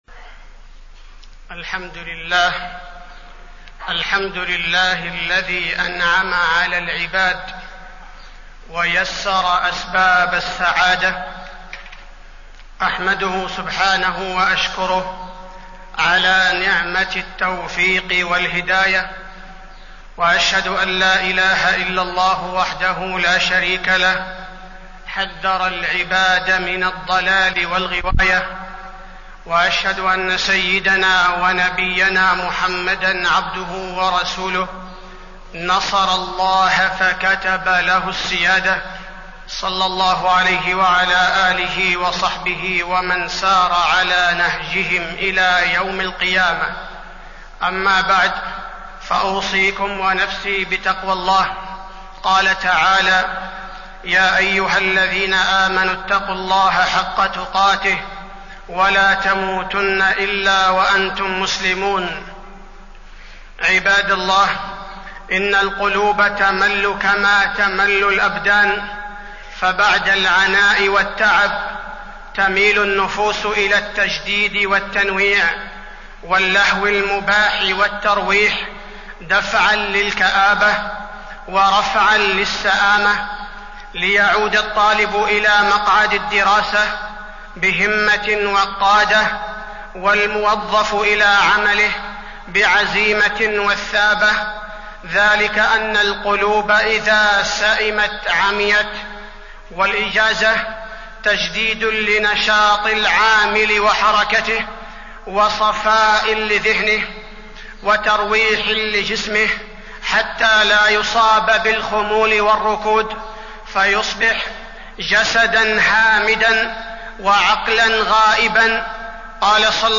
تاريخ النشر ١٣ جمادى الأولى ١٤٢٧ هـ المكان: المسجد النبوي الشيخ: فضيلة الشيخ عبدالباري الثبيتي فضيلة الشيخ عبدالباري الثبيتي استغلال الإجازة